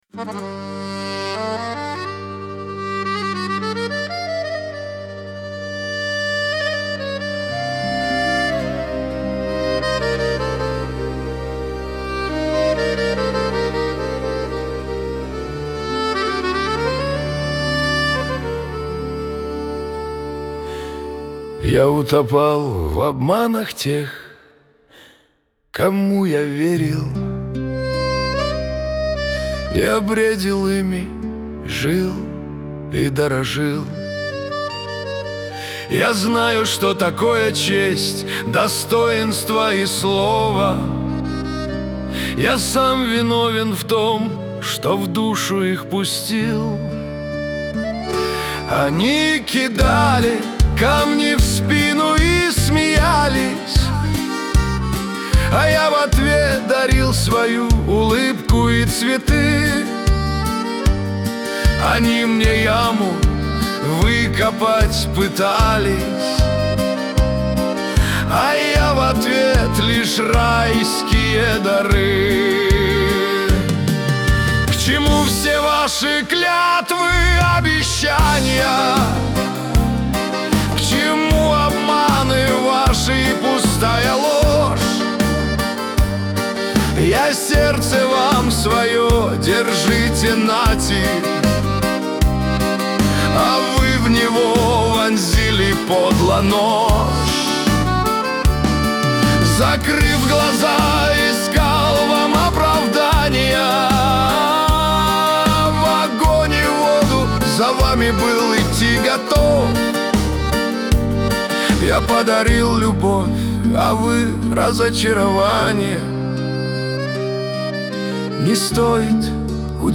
грусть , Шансон
Лирика